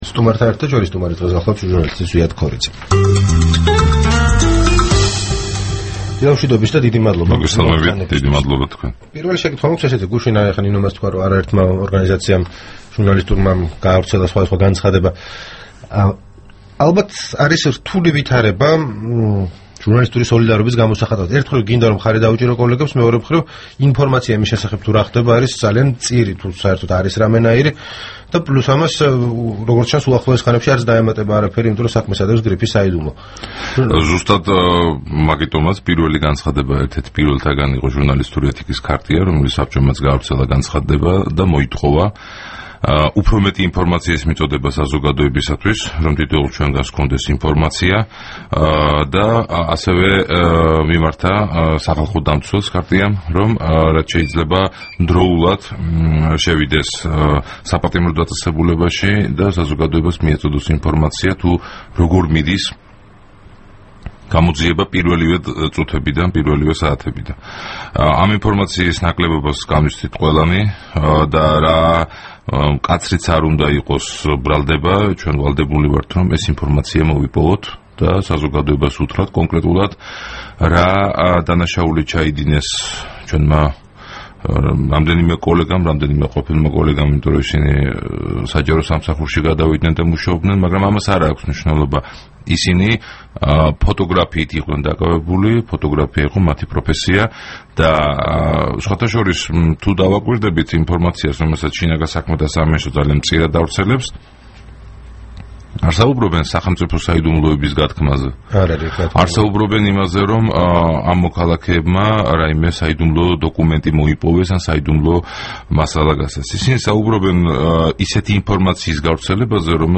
რადიო თავისუფლების თბილისის სტუდიაში
საუბარი